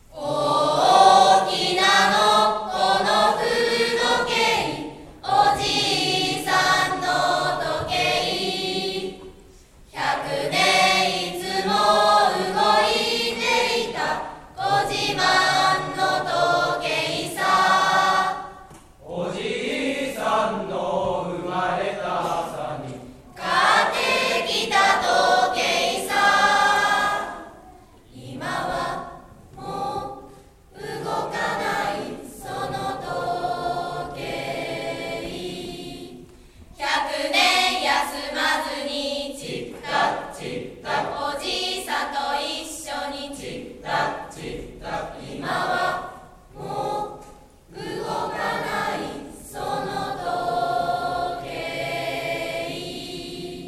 文化祭でのクラス合唱【音量に注意してください！】